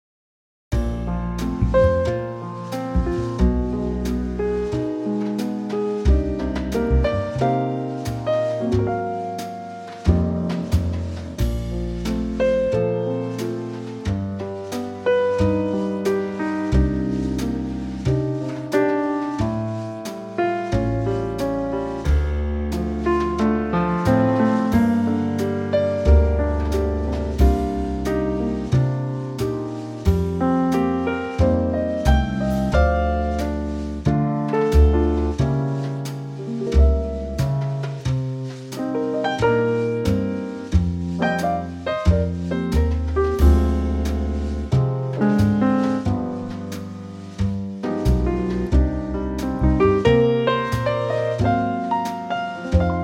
Unique Backing Tracks
key - Bb - vocal range - A to D